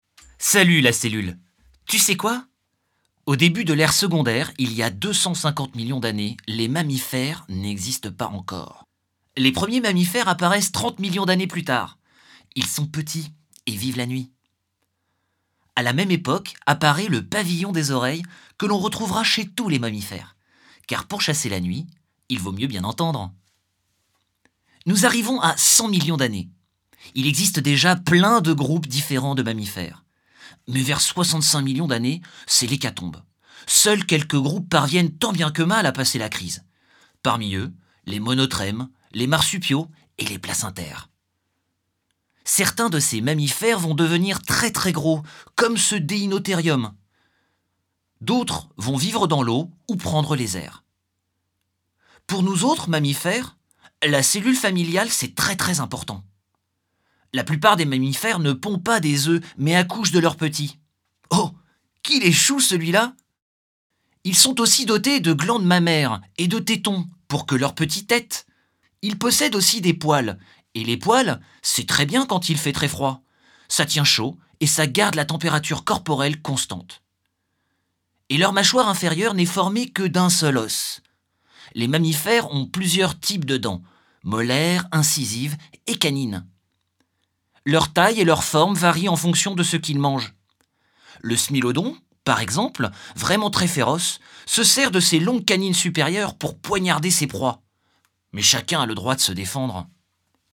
voix pour un parcours musée